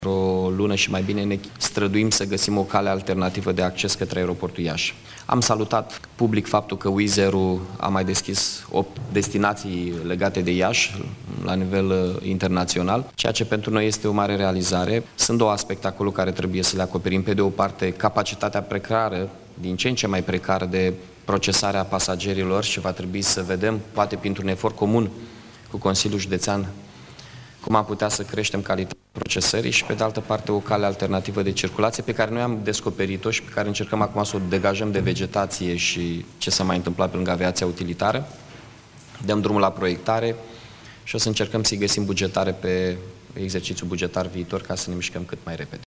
Primarul Mihai Chirica a anunțat în timpul ședinței extraordinare a Consiliului Local că municipalitatea a găsit o cale rutieră alternativă către aerogară. Banii pentru acest proiect vor fi prinși în bugetul de anul viitor.